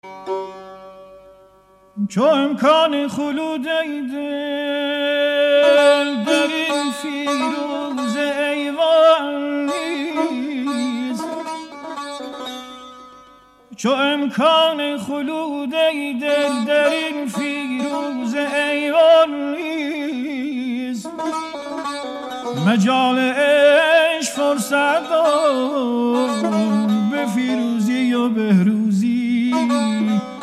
با نوای ملکوتی